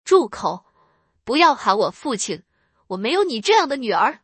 F2-angry.mp3